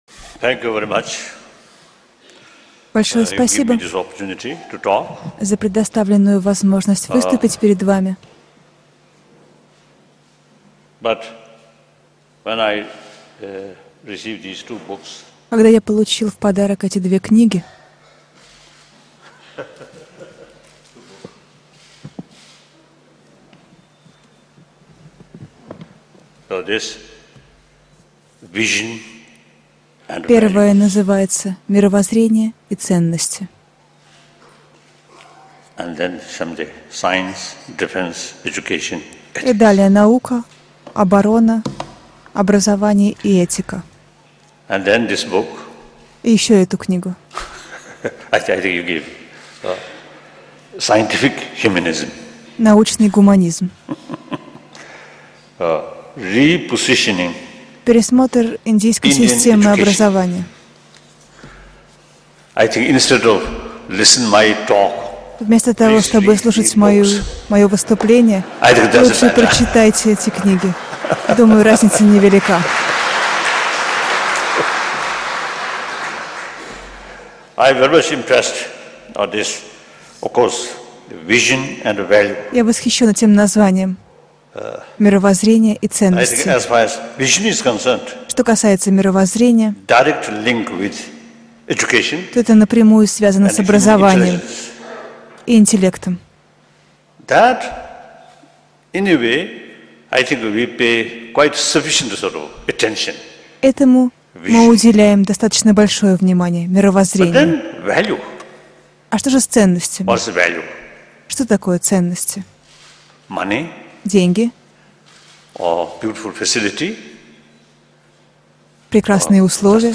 Aудиокнига Этика для нового тысячелетия Автор Далай-лама XIV.